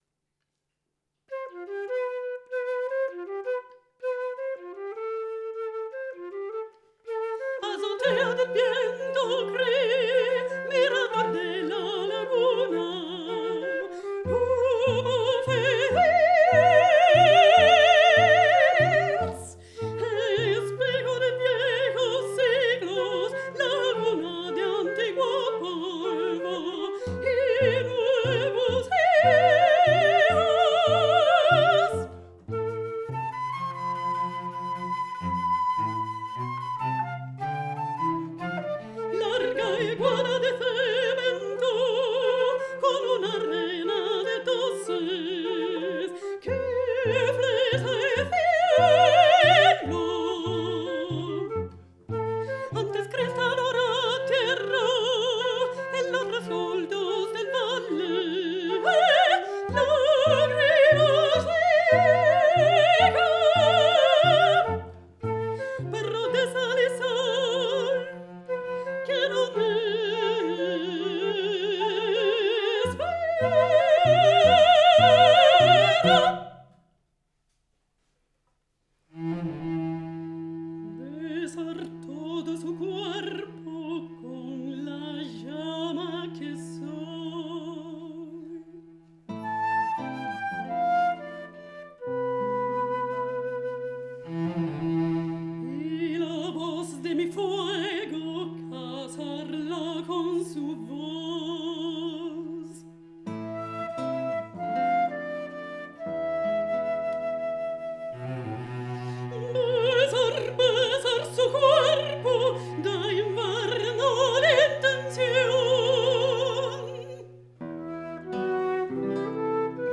mezzosoprano
flauto
violoncello
chitarra
Archivio Storico della Città di Torino
Live recording, Giugno 2007